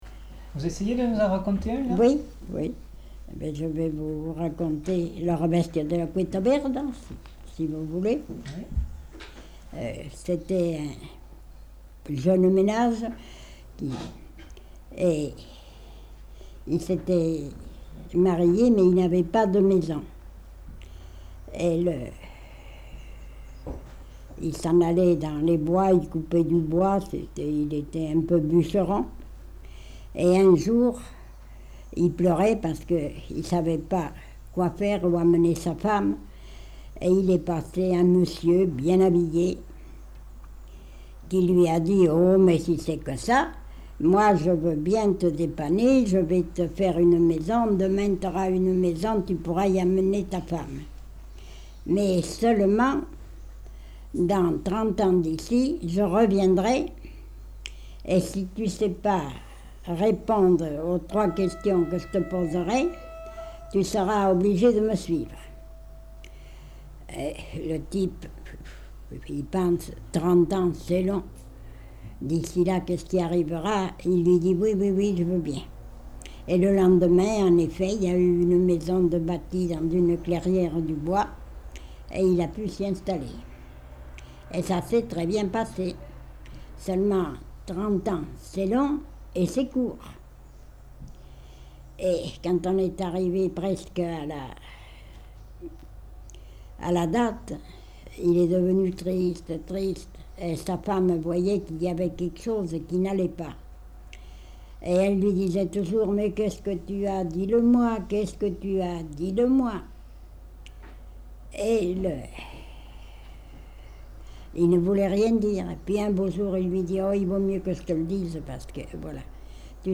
Lieu : Tournay
Genre : conte-légende-récit
Effectif : 1
Type de voix : voix de femme
Production du son : parlé